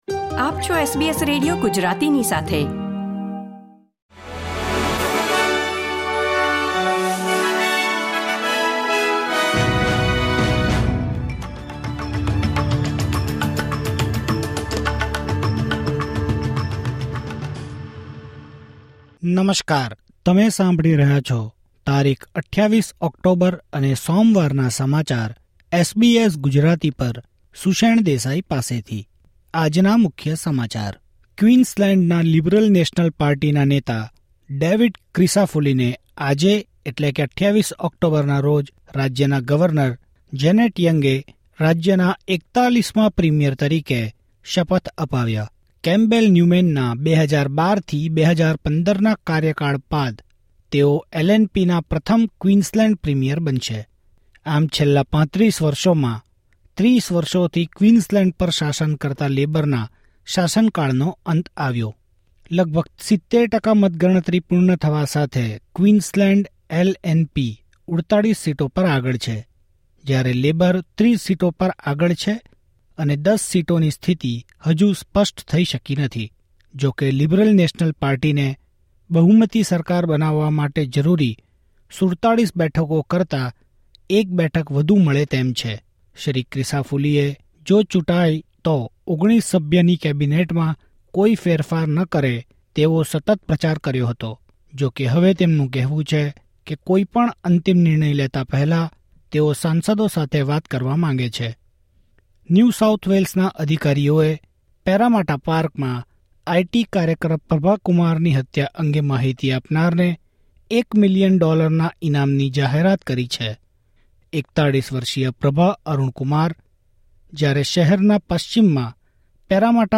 SBS Gujarati News Bulletin 28 October 2024